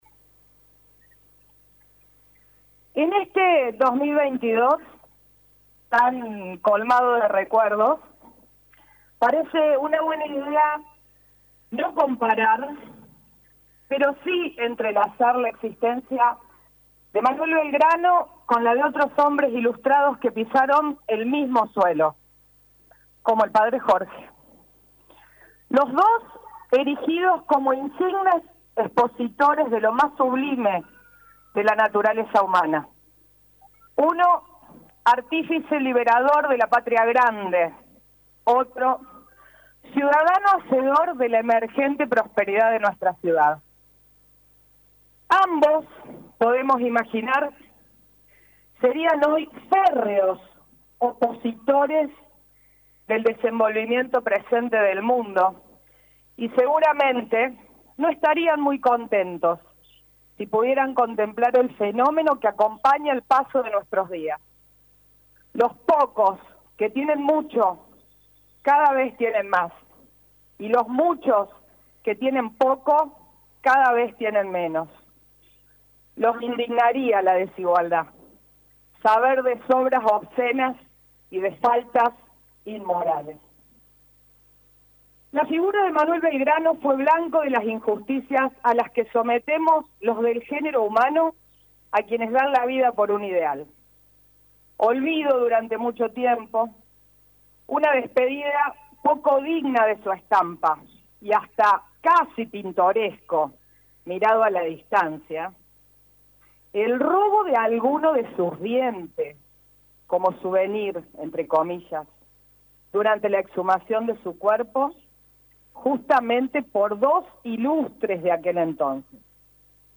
El acto se desarrolló en la Plaza San Martín con la presencia de autoridades institucionales, profesores y alumnos del Complejo Educativo Padre Jorge Isaac.